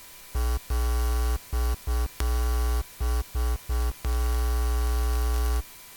Plug it into the UCA-202 inputs and listen on the headphones while you touch the hot end of the cable.
Turn the headphone volume control up. 539×600 17 KB You should get a buzz sound like this. /uploads/default/original/3X/a/2/a2f394aa2fc9ace16f90149e5bf95bd1b2c76c01.mp3 This has been volume boosted. It may not be very loud.